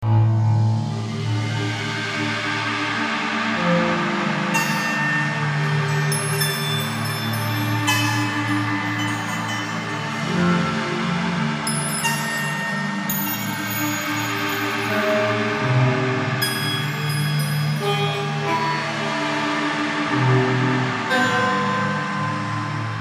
海浪和海洋
它基本上是海浪飞溅到海岸和岩石上，我在我的一个轨道中使用了这个，它是一个很好的样本......如果你想让它持续更长的时间，只要循环它！
标签： 95 bpm Ambient Loops Fx Loops 3.87 MB wav Key : Unknown
声道立体声